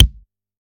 soft-hitnormal.ogg